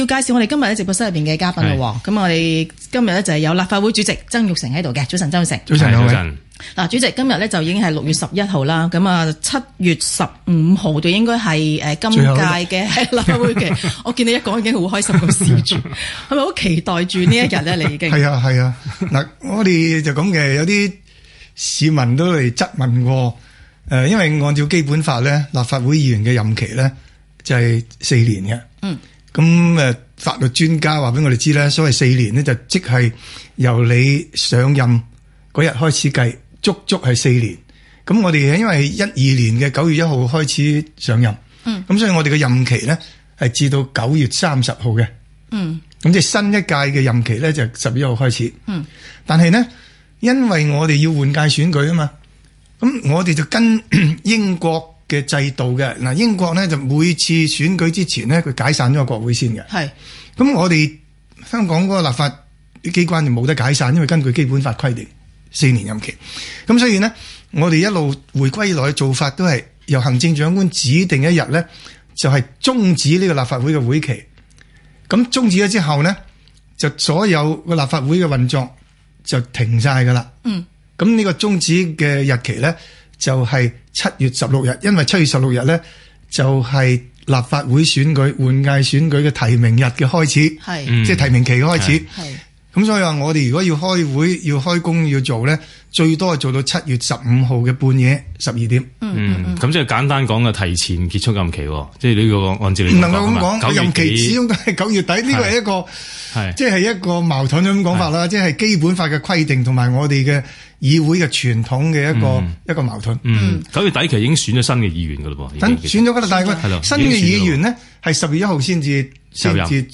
香港電台《星期六問責》訪問